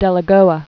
(dĕlə-gōə)